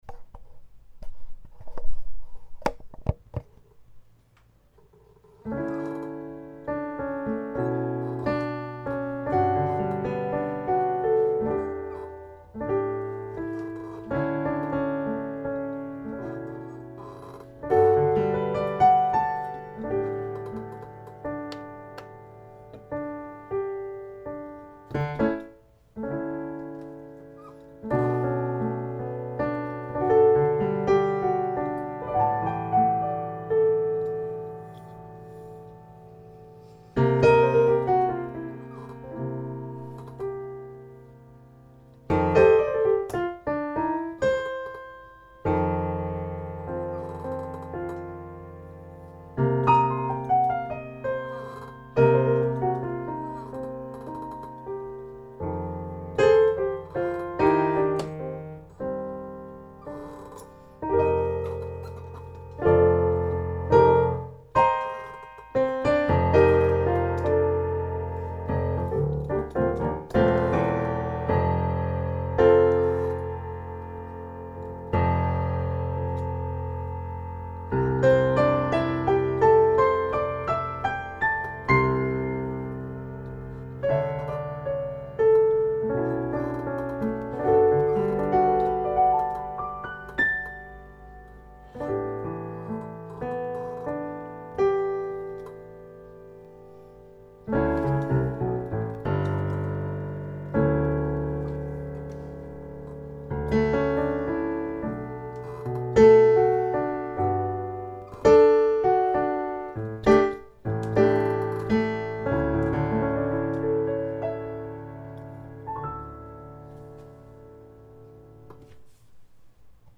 "DR0000_0761.mp3" (「TASCAM DR-07MK2」より)
piano.mp3